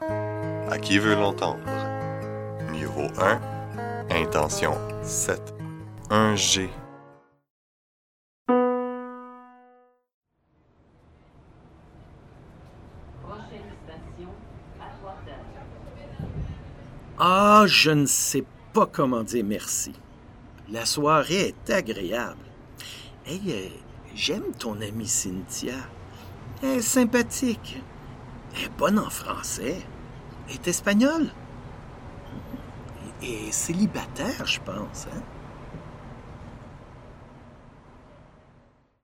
Indicatif présent : Associer [ε:] à elle est o